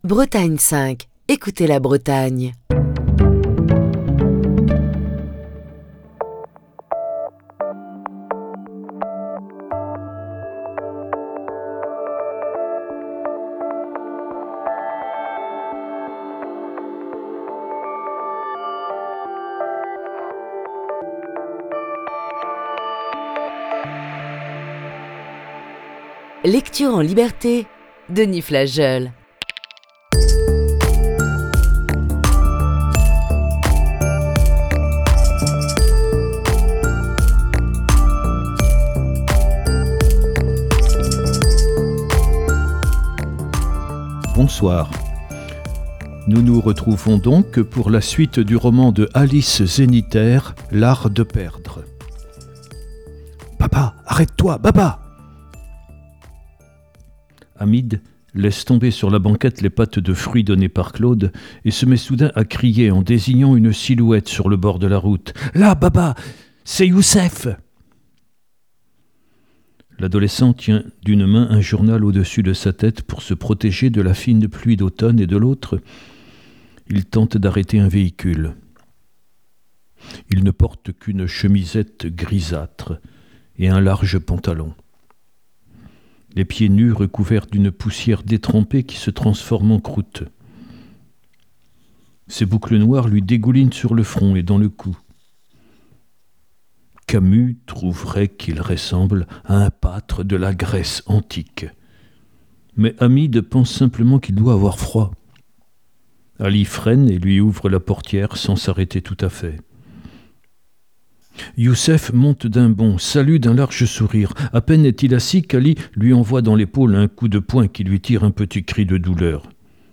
Émission du 9 février 2022.